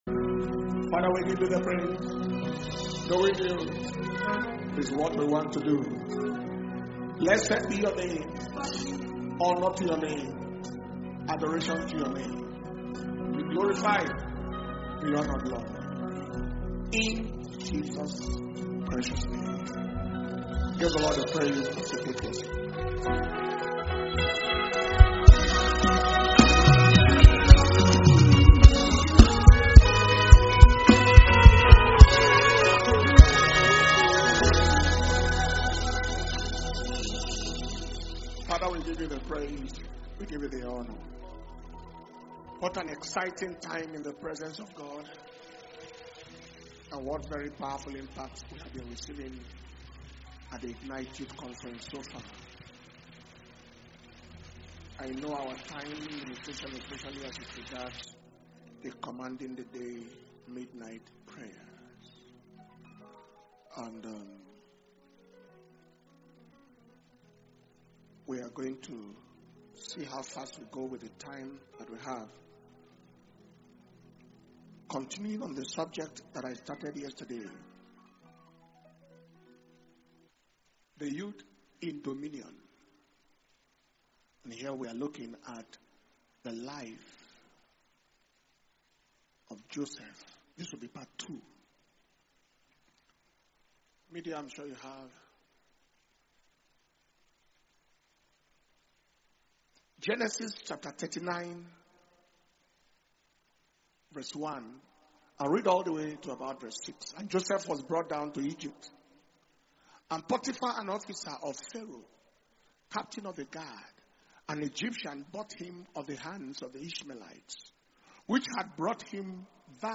Dunamis Ignite Conference Monday August 4th 2025 – Day Two Evening Session